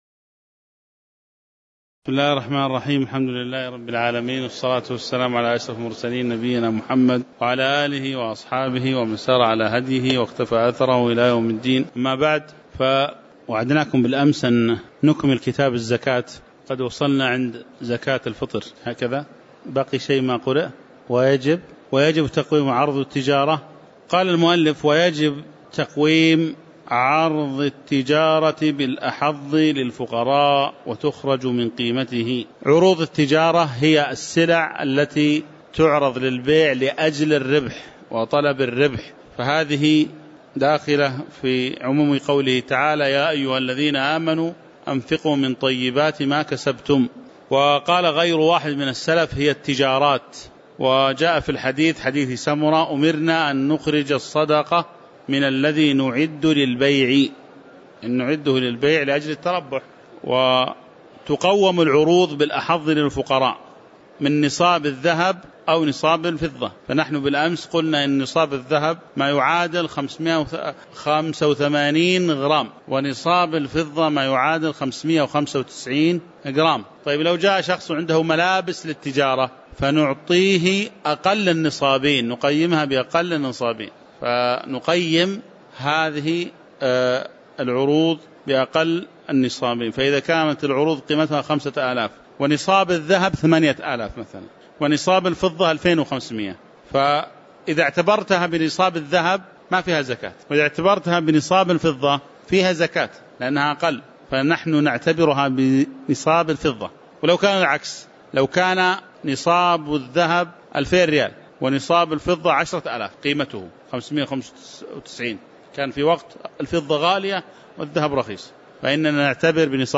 تاريخ النشر ٢١ رجب ١٤٤٠ هـ المكان: المسجد النبوي الشيخ